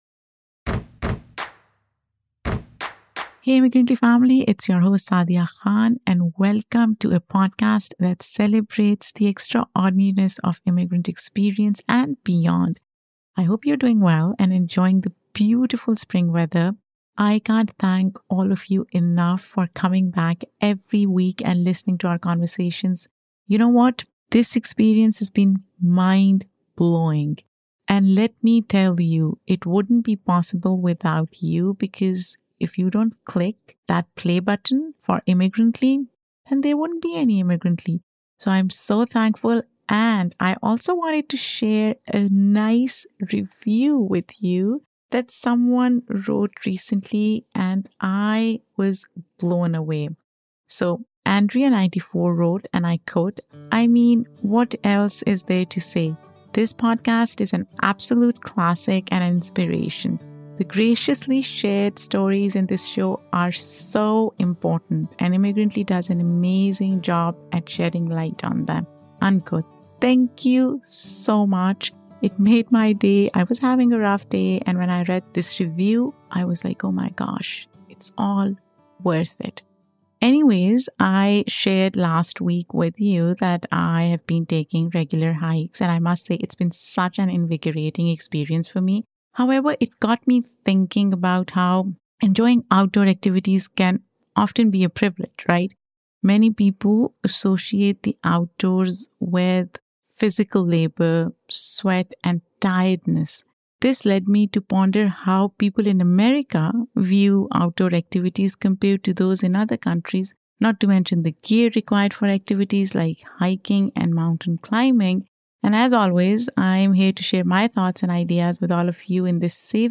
I loved talking to her about her writing process, the inspiration behind her work, and her experiences as a Sri Lankan American. It was a rich conversation that I can't wait for you all to hear.